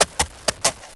Heroes3_-_War_Unicorn_-_MoveSound.ogg